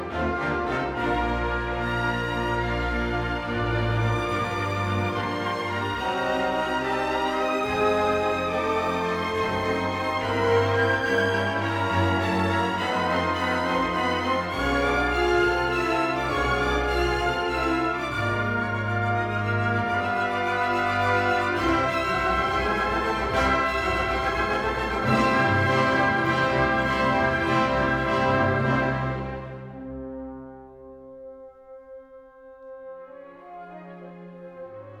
# Классика